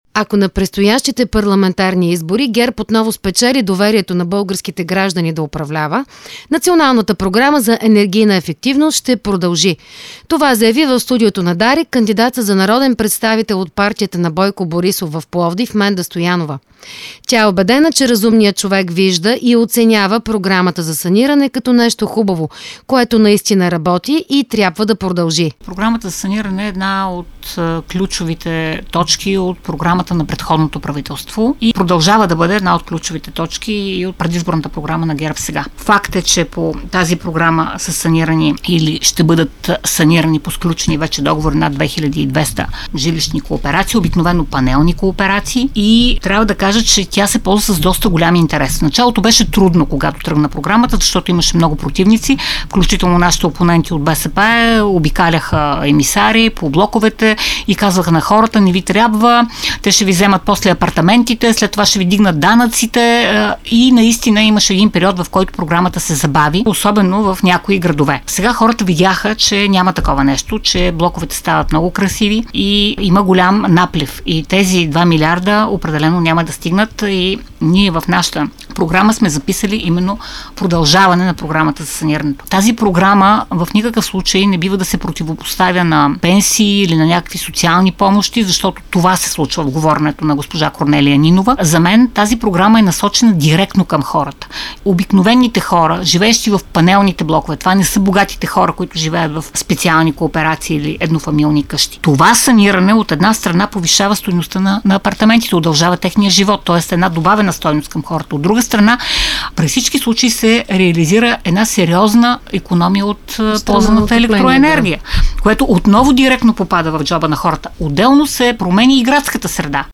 Това заяви в студиото на Дарик радио кандидатът за народен представител на ПП ГЕРБ в Пловдив Менда Стоянова.